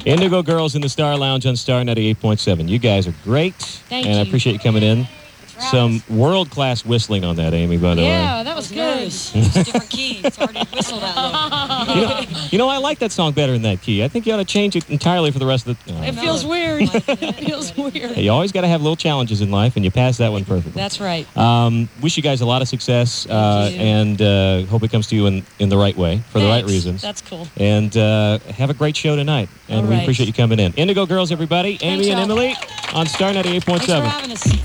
lifeblood: bootlegs: 1997: 1997-07-03: the star - los angeles, california
06. interview (0:34)